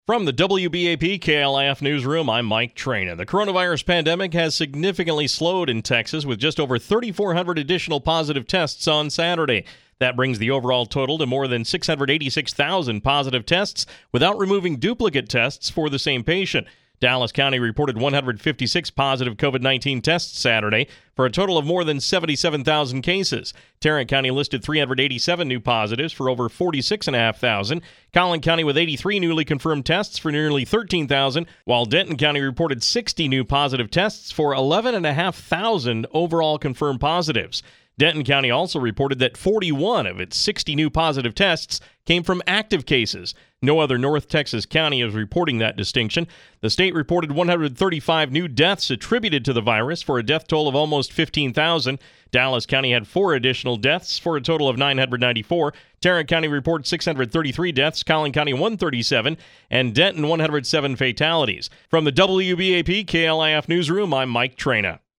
6PM-CORONAVIRUS-UPDATE.mp3